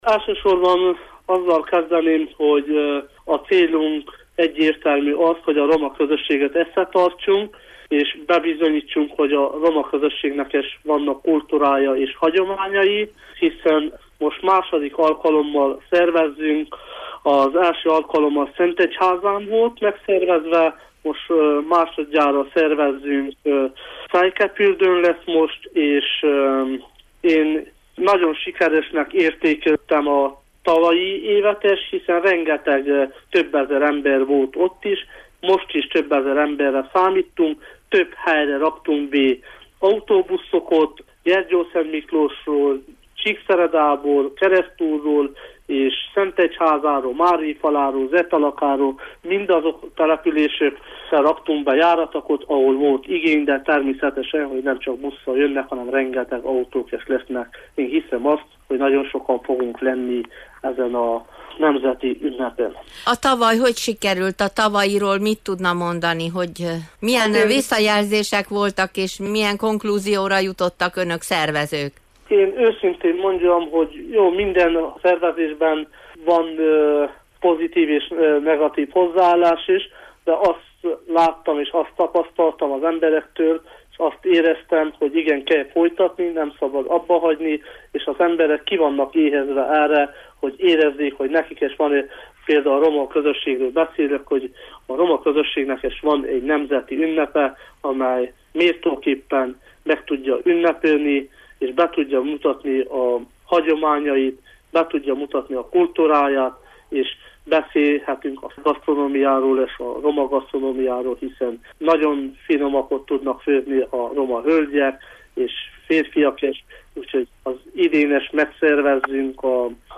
Addig is hallgassuk meg a vele készült egyfajta portré interjút: